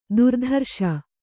♪ durdharṣa